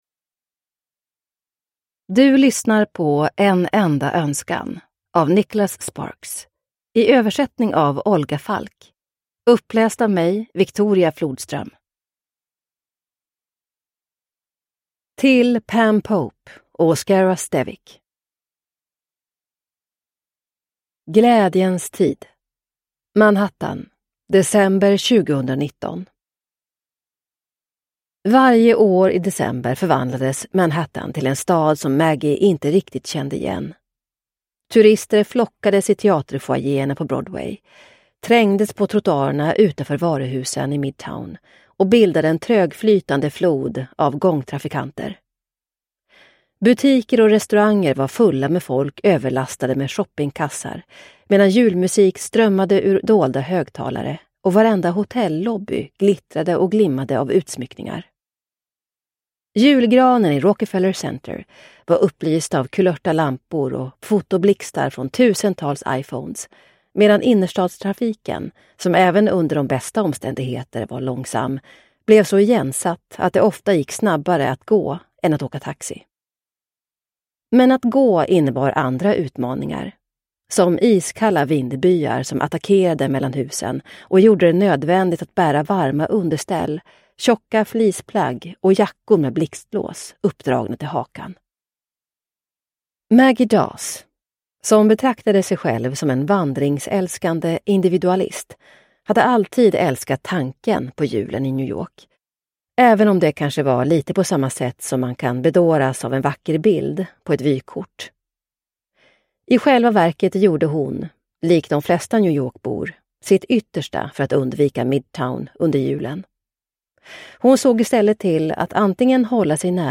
En enda önskan – Ljudbok – Laddas ner